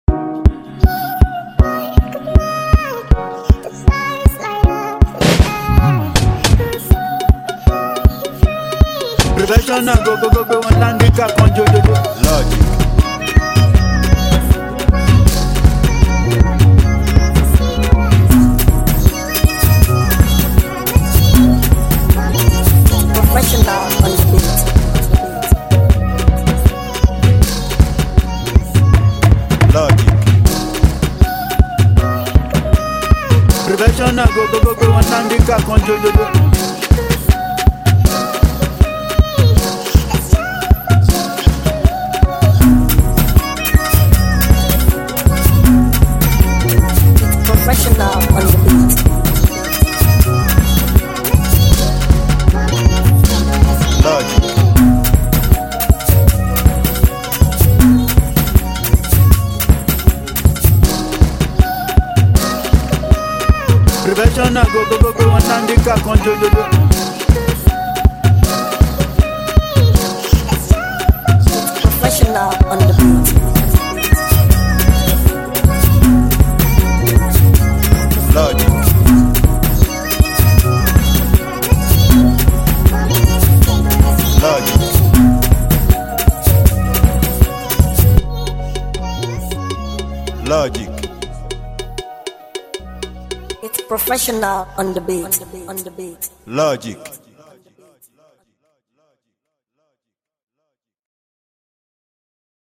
Free Beat